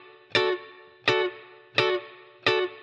DD_TeleChop_85-Dmaj.wav